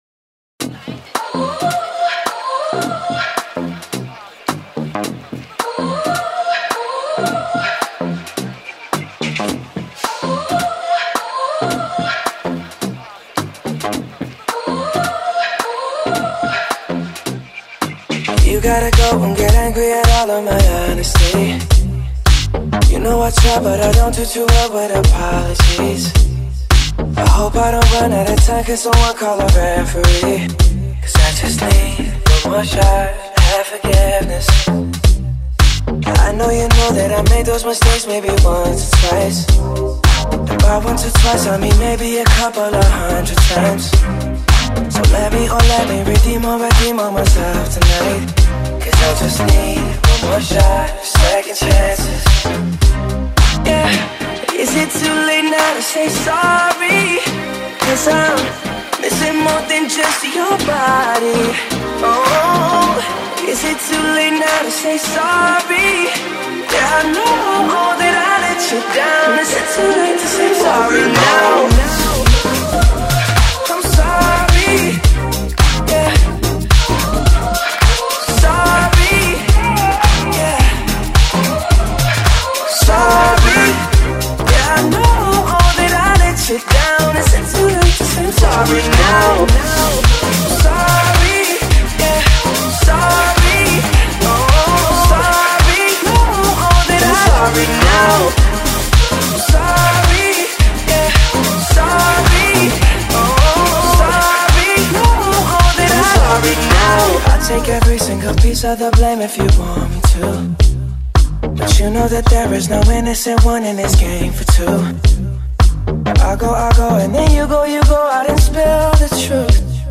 al más puro sonido Garage House